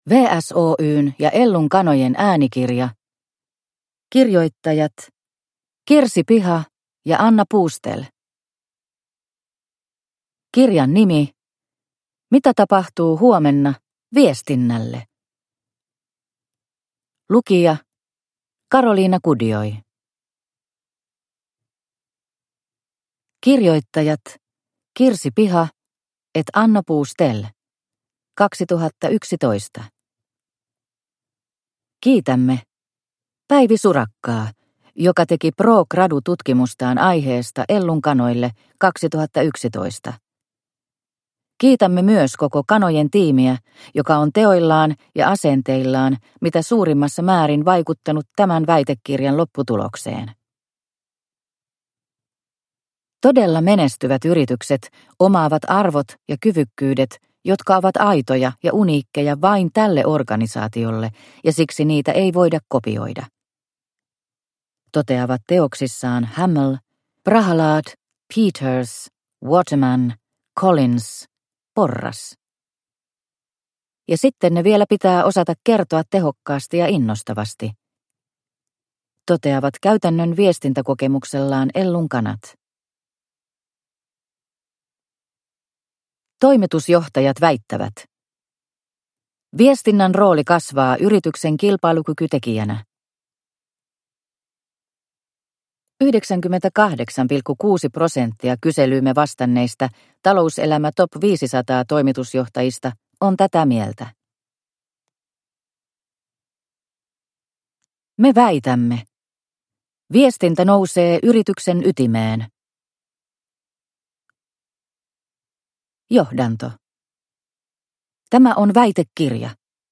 Mitä tapahtuu huomenna viestinnälle? – Ljudbok